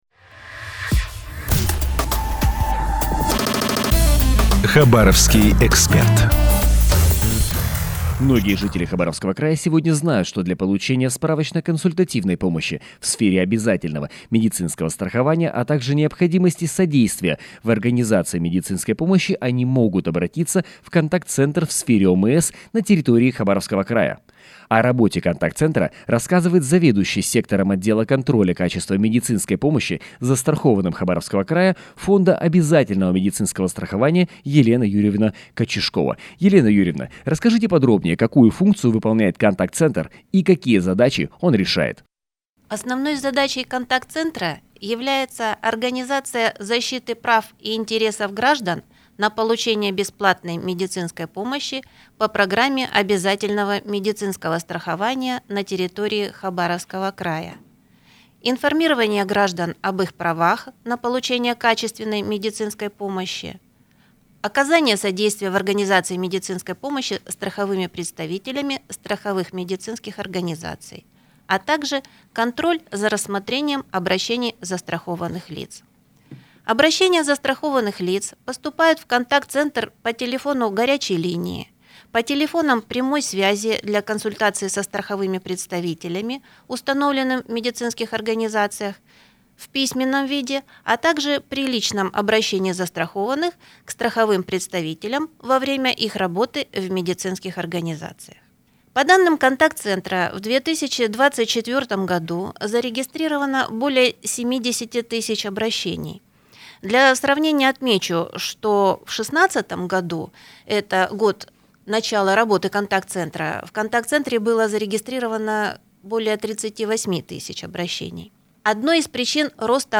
Выступление на радио